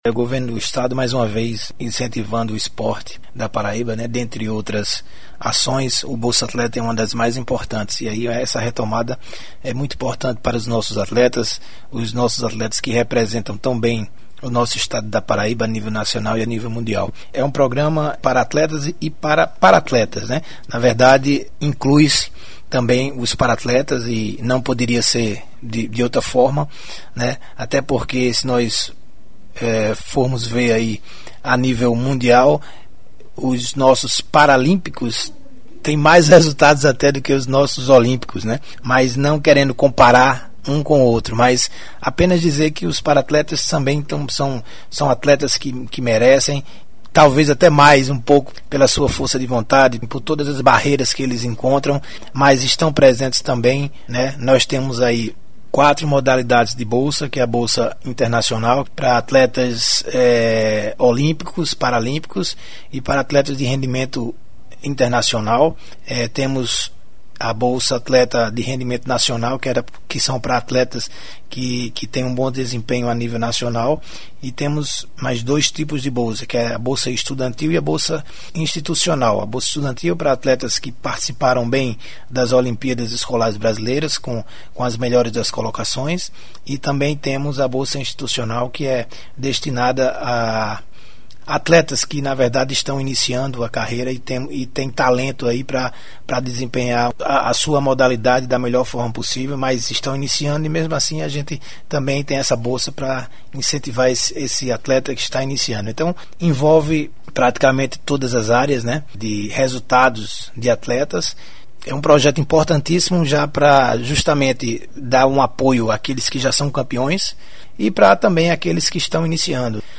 Acompanhe clicando nos links em anexo sonoras de entrevista produzida no estúdio da Secretaria de Estado da Comunicação Institucional com o secretário da Juventude, Esporte e Lazer, José Marco.